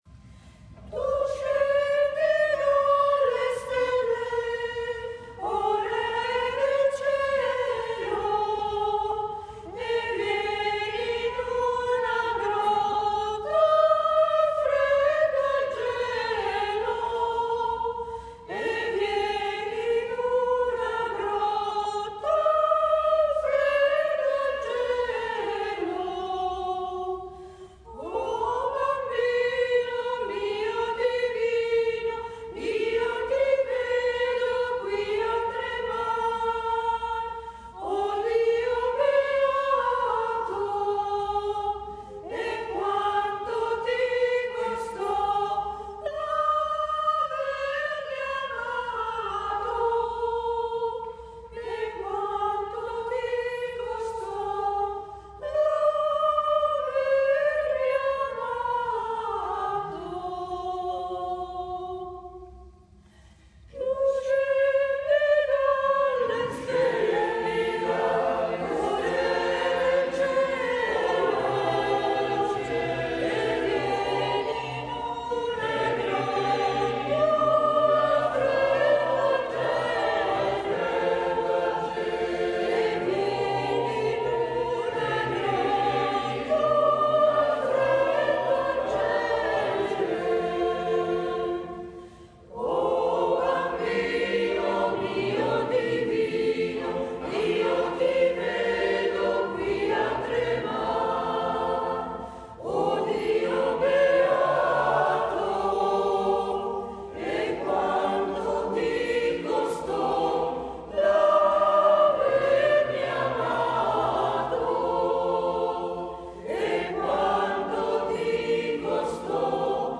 Concerto di Natale 15 Dicembre 2018 - RESCALDA